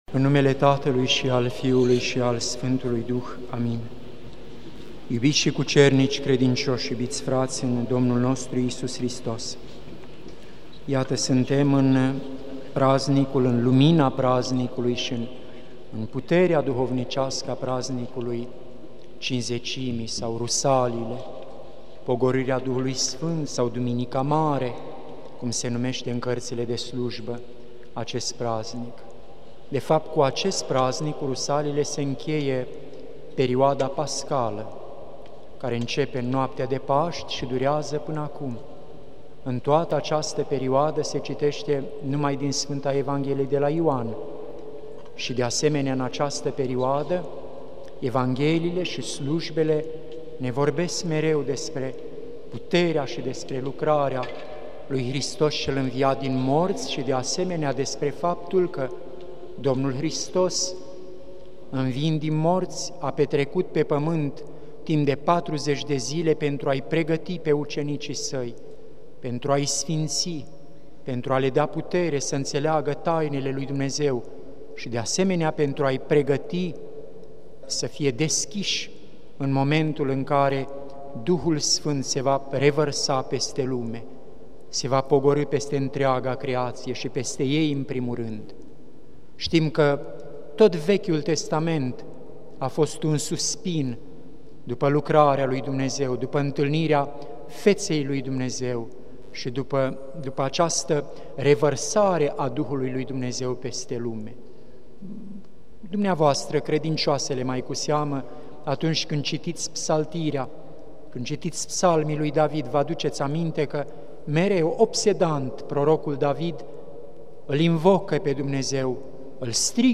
Predică la sărbătoarea Sfintei Treimi
Cuvinte de învățătură Predică la sărbătoarea Sfintei Treimi